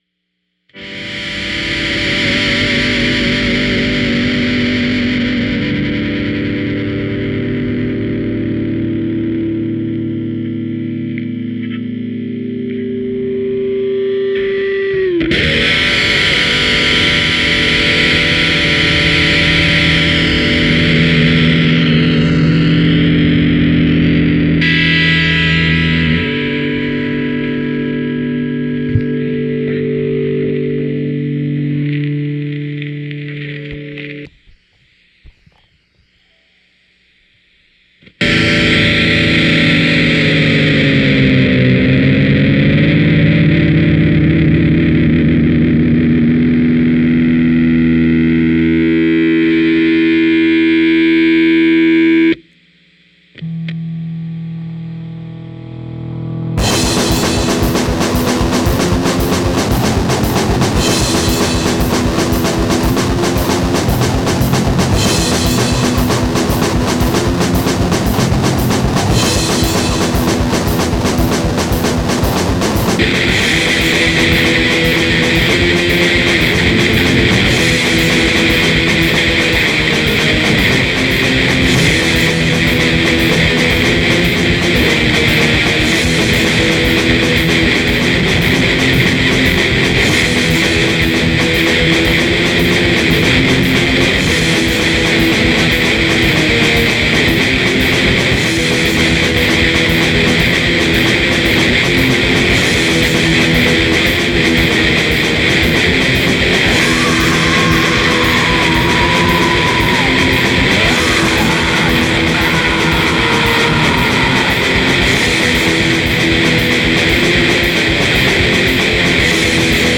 Jam